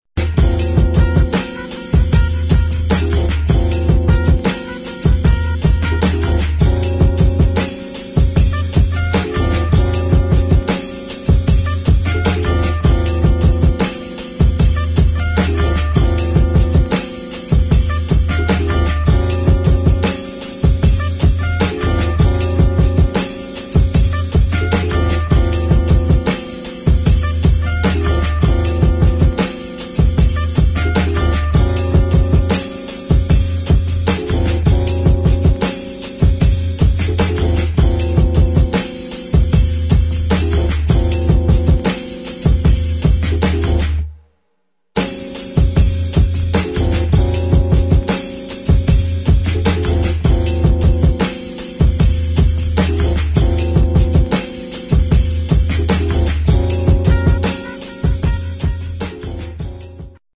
[ HIP HOP ]
Instrumental